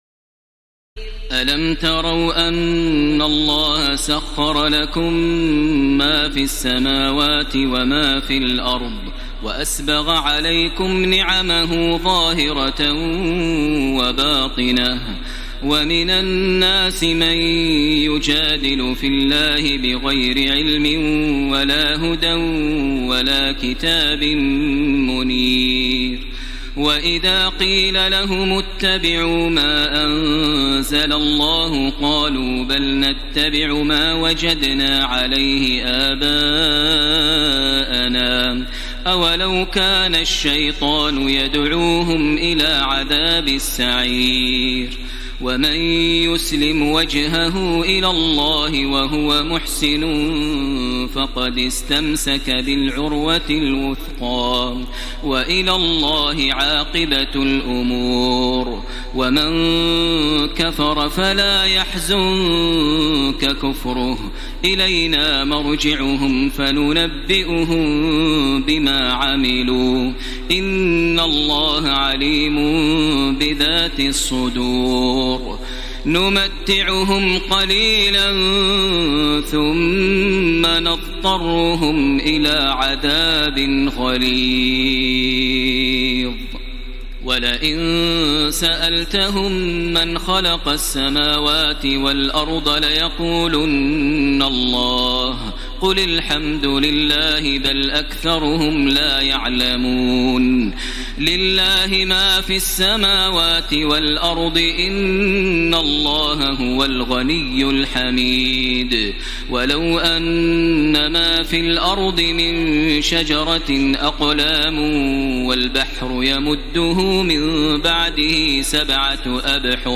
تراويح الليلة العشرون رمضان 1434هـ من سور لقمان (20-34) والسجدة و الأحزاب (1-27) Taraweeh 20 st night Ramadan 1434H from Surah Luqman and As-Sajda and Al-Ahzaab > تراويح الحرم المكي عام 1434 🕋 > التراويح - تلاوات الحرمين